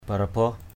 /ba-ra-bɔh/ (cv.) parabaoh pr_b<H